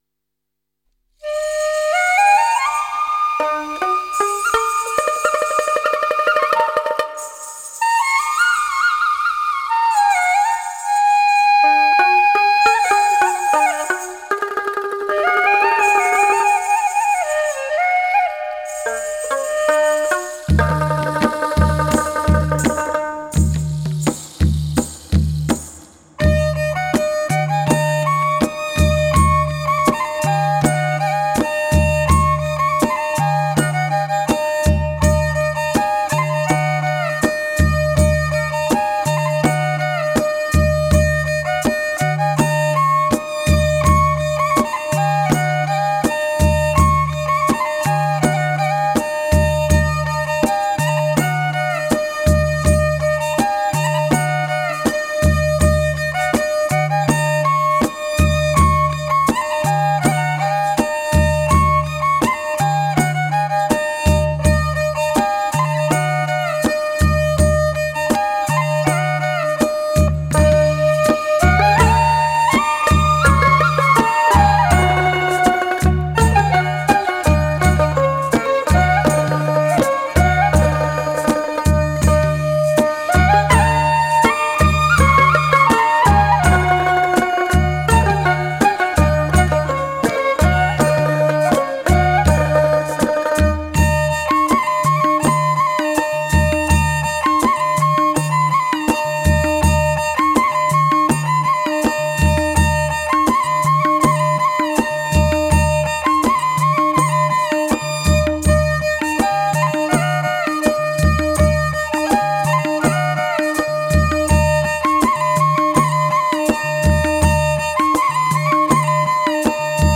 Click here to listen to music straight out of Nepal: [Wait for the tune to download … it runs about 6 minutes.]